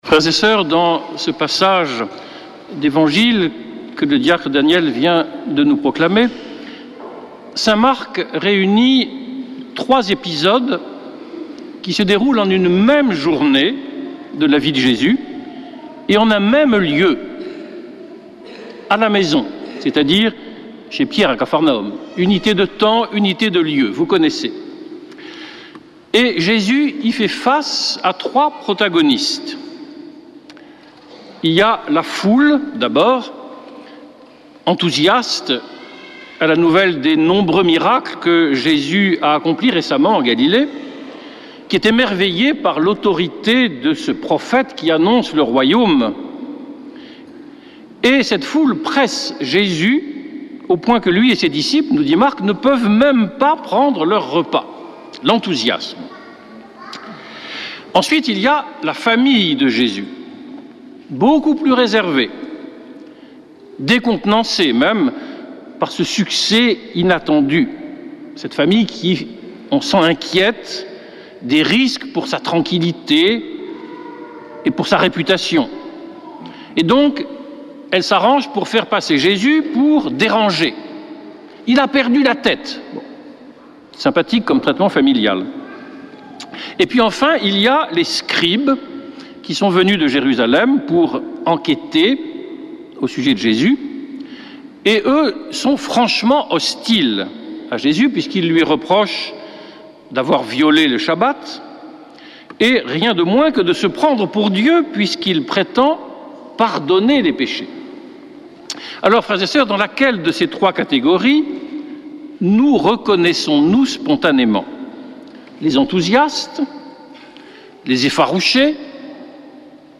Messe depuis le couvent des Dominicains de Toulouse
Homélie du 09 juin